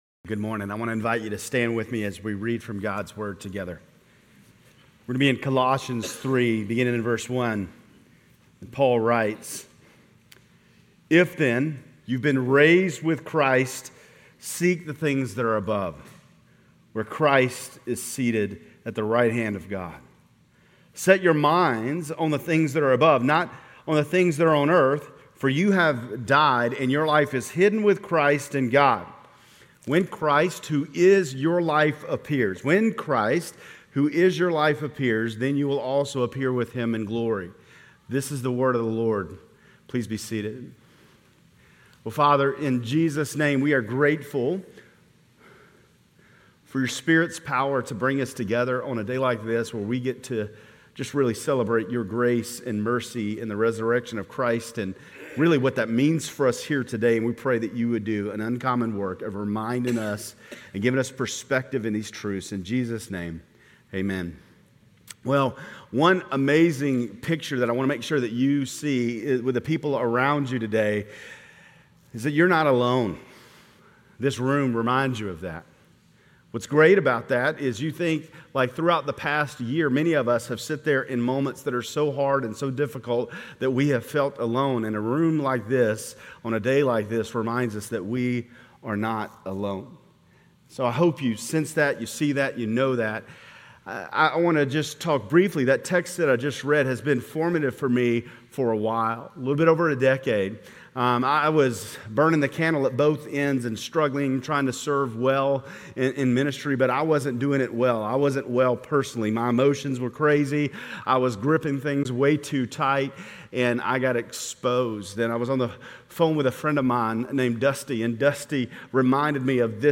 Grace Community Church Lindale Campus Sermons Easter Sunday Apr 01 2024 | 00:26:11 1x 00:00 / 00:26:11 Subscribe Share RSS Feed Share Link Embed